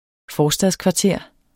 Udtale [ ˈfɒːsdaðs- ]